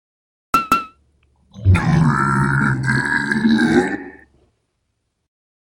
fire-3.ogg.mp3